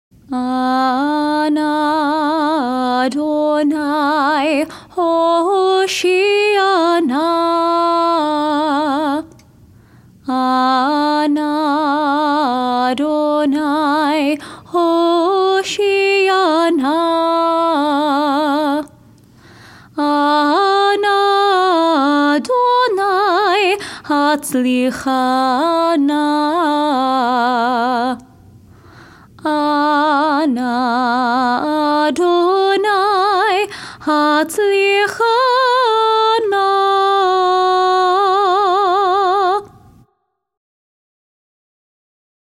Festival Shaharit (Higher Voice)